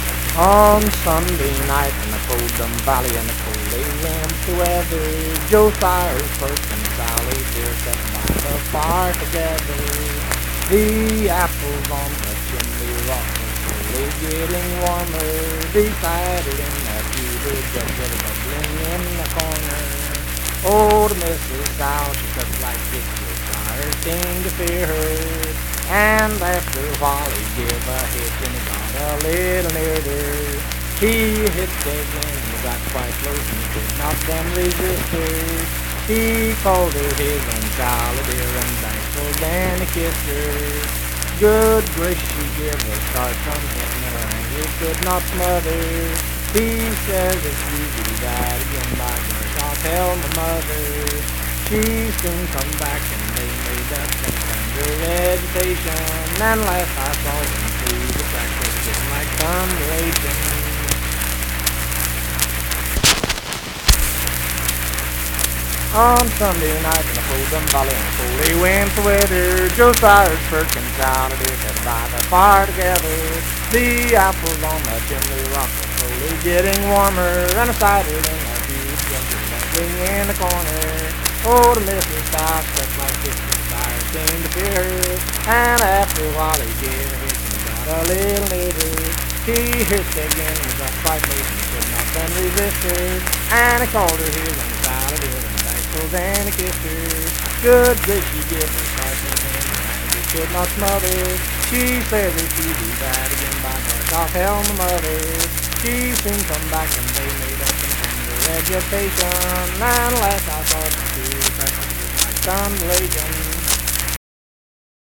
Unaccompanied vocal music
Verse-refrain 6(4).
Voice (sung)